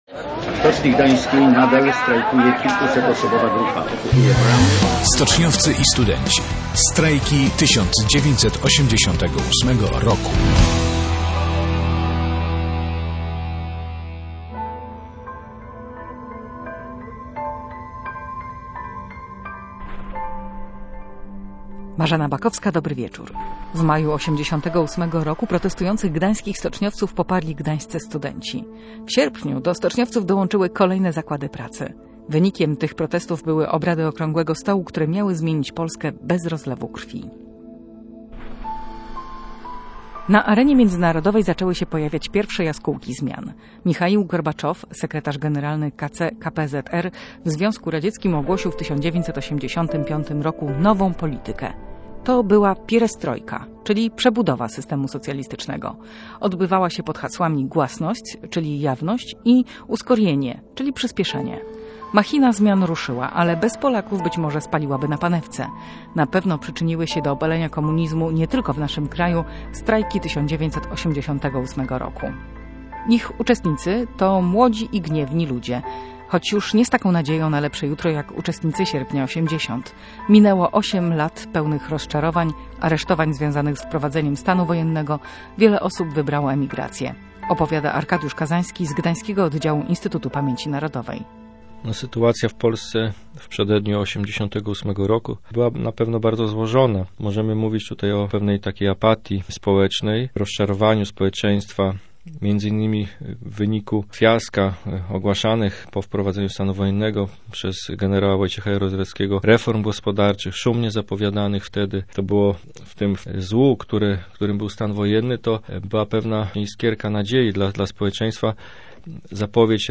Przypominamy też archiwalne wypowiedzi Jana Pawła II./audio/dok2/stoczniowcy1406.mp3